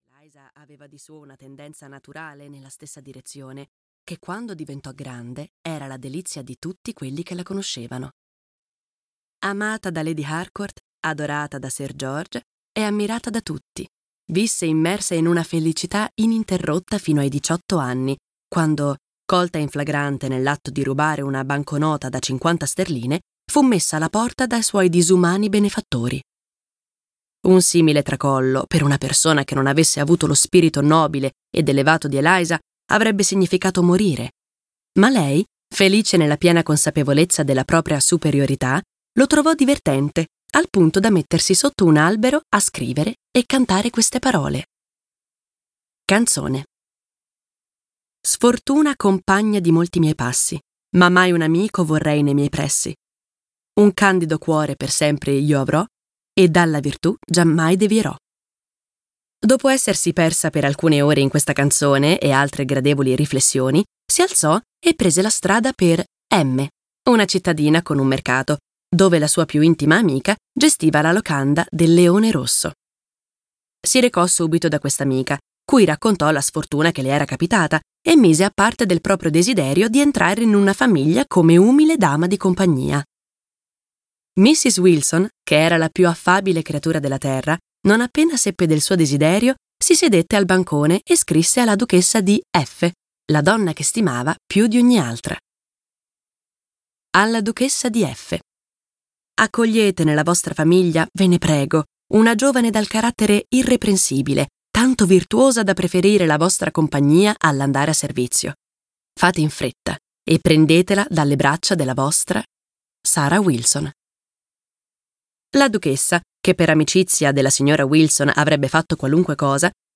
"La bella Cassandra" di Jane Austen - Audiolibro digitale - AUDIOLIBRI LIQUIDI - Il Libraio